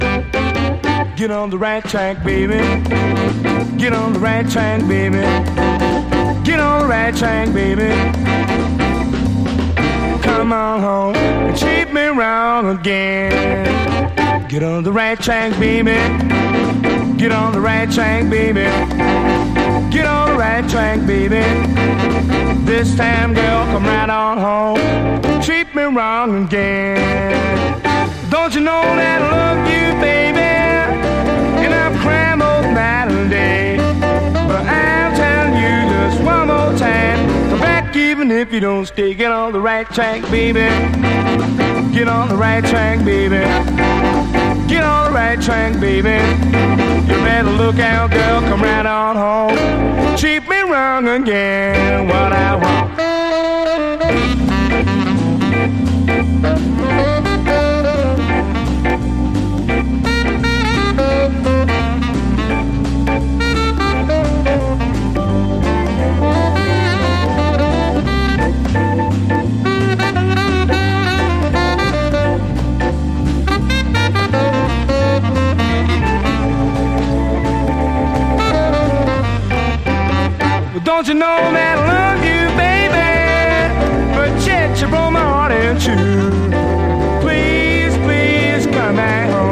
モッド・クラシックなキラー・カヴァー多数の1964年録音！